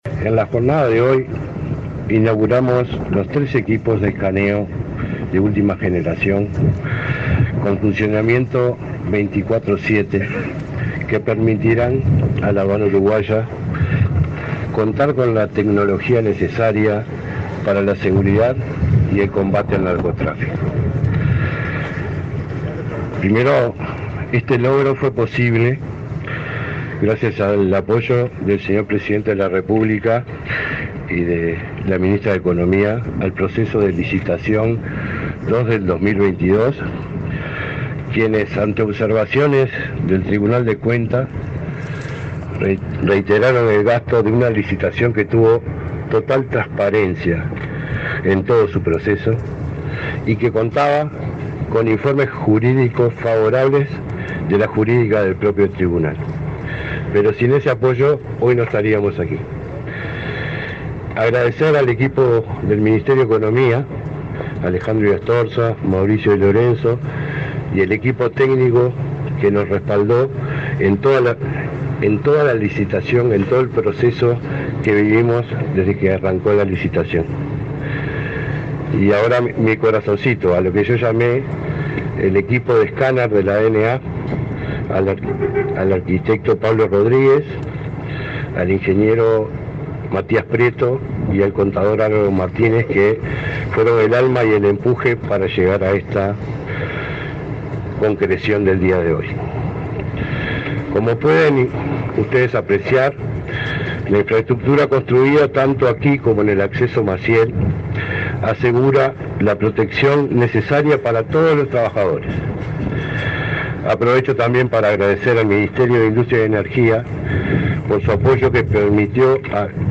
Palabras de autoridades en acto en el puerto de Montevideo
Palabras de autoridades en acto en el puerto de Montevideo 02/10/2024 Compartir Facebook X Copiar enlace WhatsApp LinkedIn Este miércoles 2, el director nacional de Aduanas, Jaime Borgiani, y la ministra de Economía, Azucena Arbeleche, se expresaron en el acto de inauguración de la operativa de nuevos escáneres en el puerto de Montevideo.